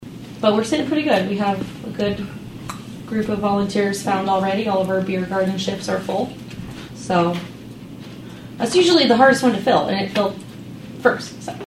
speaking at the Atlantic City Council meeting